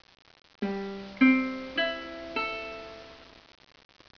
El CUATRO
Algunas de las afinaciones normales antiguas del cuatro son La, Re, Fa sost., Si, y